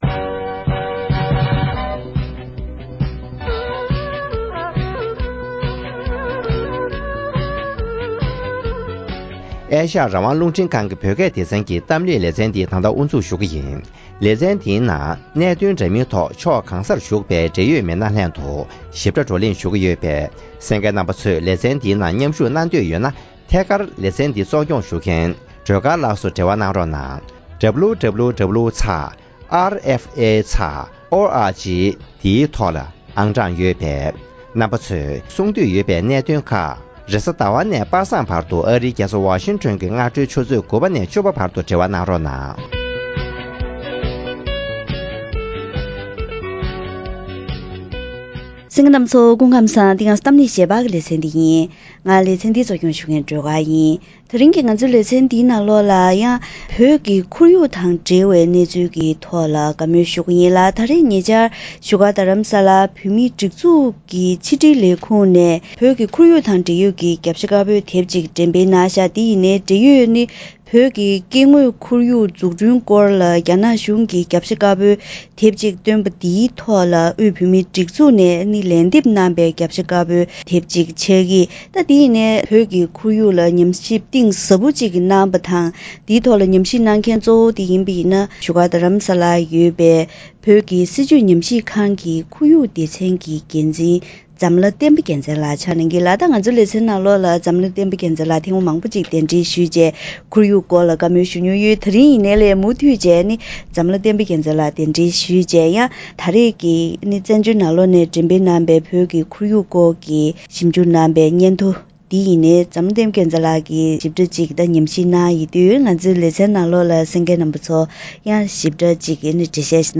བོད་མཐོ་སྒང་གི་ཁོར་ཡུག་ལ་སྲུང་སྐྱོབ་བྱ་རྒྱུ་འདི་ད་ལྟའི་དུས་སུ་གང་ལས་ཀྱང་གལ་འགངས་ཆེ་བ་ཆགས་ཡོད་པའི་ཐད་བགྲོ་གླེང་ཞུས་པ།